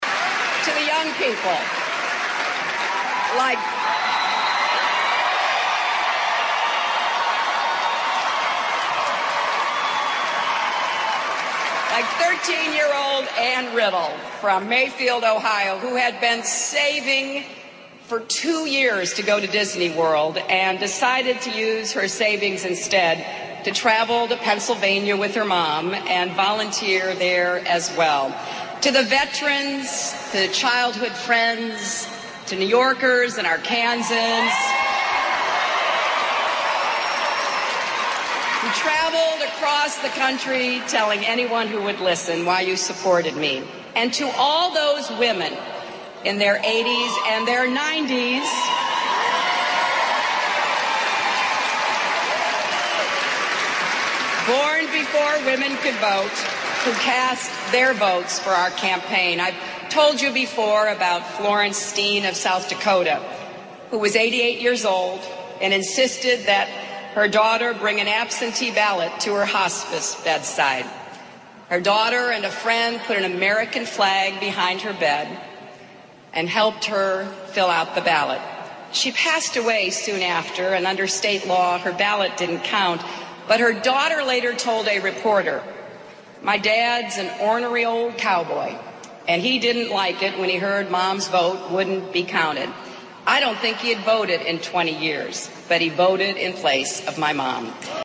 名人励志英语演讲 第68期:我放弃了 但我会继续战斗(2) 听力文件下载—在线英语听力室